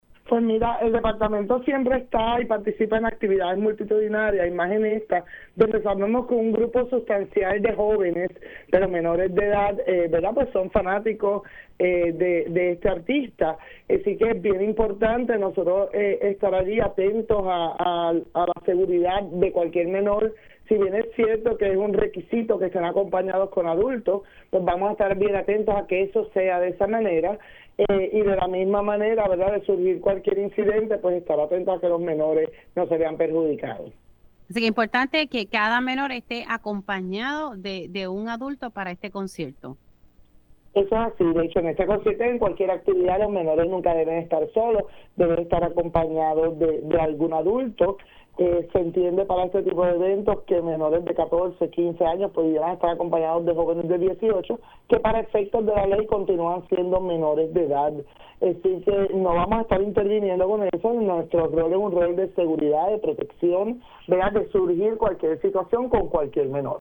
La información fue confirmada por la secretaria del Departamento de la Familia (DF), Suzanne Roig, en Pega’os en la Mañana, quien informó que desplegarán a su personal durante la residencia de conciertos del artista urbano.